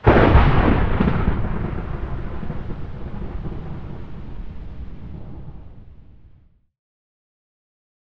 thunder3.mp3